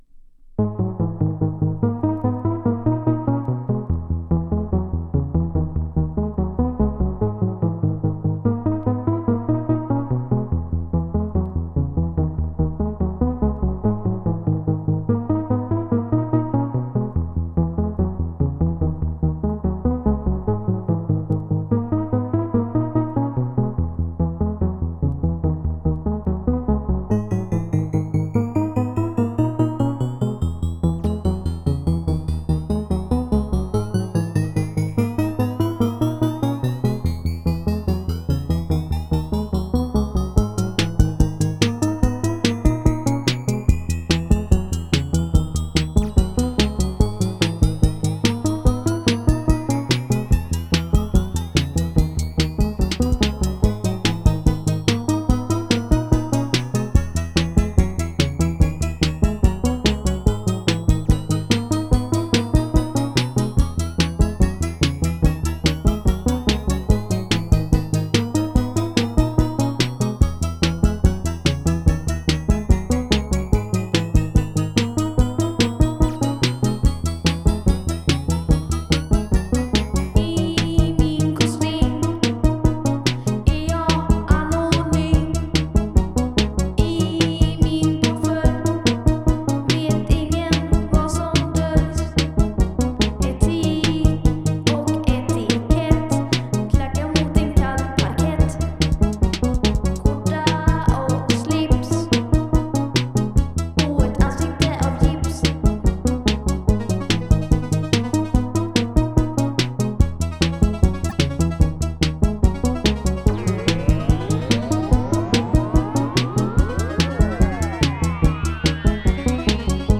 ont commis un deux titre synthwave hyper gracieux en 2010